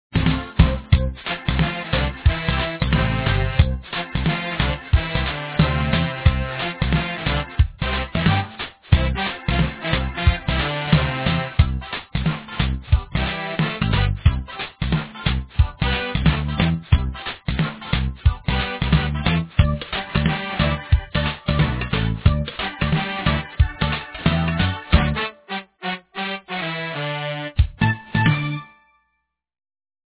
Hawaiian Music